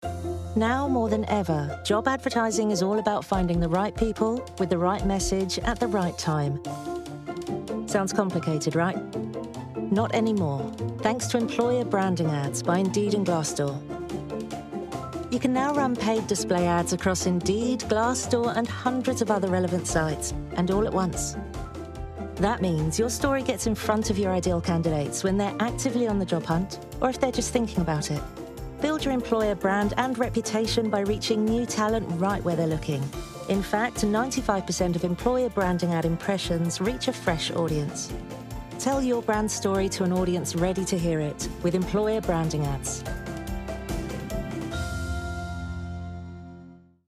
Natural, Llamativo, Accesible, Versátil, Cálida
Corporativo